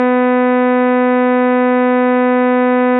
Exemple de signal périodique. 15 composantes harmoniques sont visibles sur le spectre. La fréquence fondamentale est 250 Hz. Les harmoniques ont pour fréquences : 500, 1000, 1500, 2000, … , 3750 Hz.
A l’éccoute, les musiciens peuvent reconnaitre une note qui sonne un quart de ton au-dessus d’un “La”.
exemple_signal_periodique_serie_Fourier.mp3